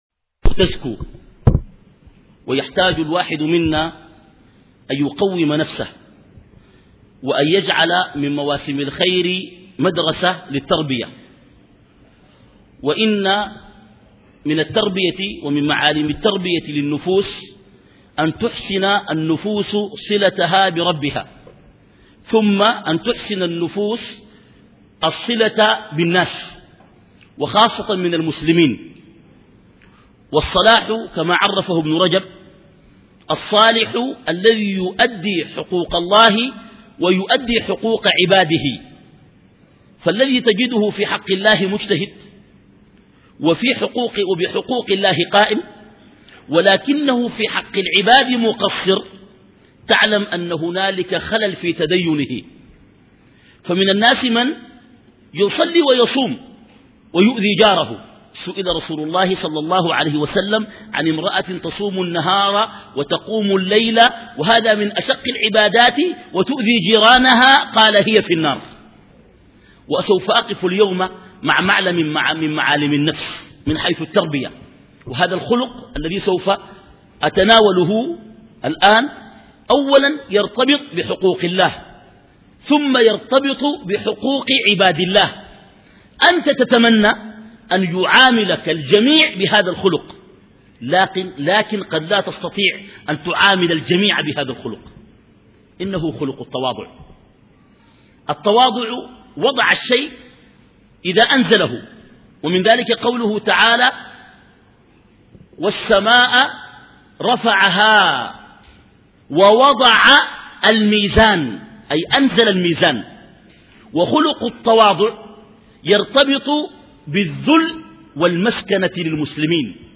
محاضررات